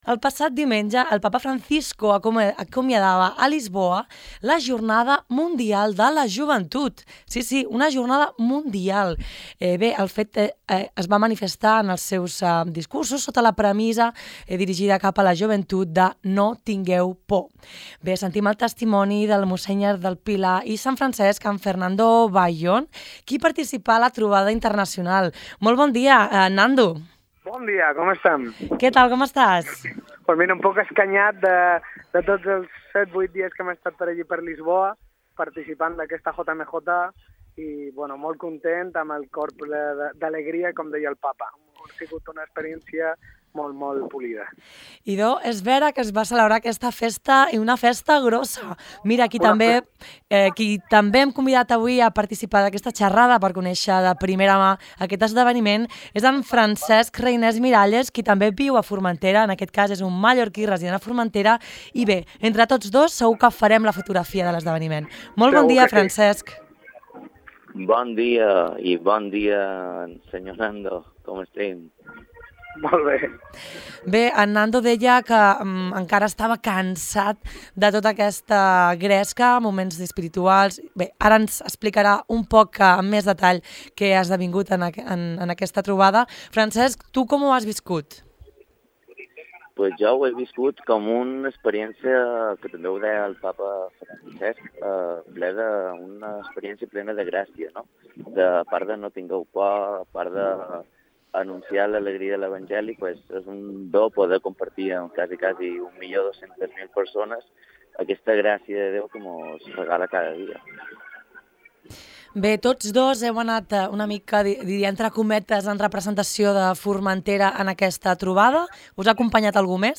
Tots dos descriuen un clima de companyonia, alegria i espiritualitat, present entre la participació. Escolta el testimoni sobre la trobada internacional de mà dels seus protagonistes, clica a continuació per escoltar l’entrevista completa.